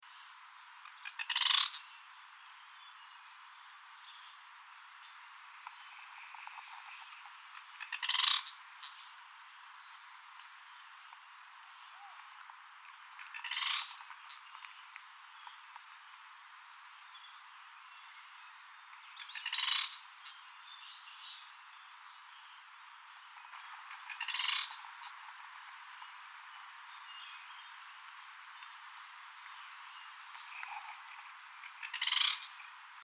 Slaty Elaenia (Elaenia strepera)
Microfono Sennheiser ME66 + K6 (Rycote Grip+Windscreen)
Grabadora Marantz PDM 661
Sex: Male
Life Stage: Adult
Location or protected area: Parque Provincial Potrero de Yala
Condition: Wild
Certainty: Observed, Recorded vocal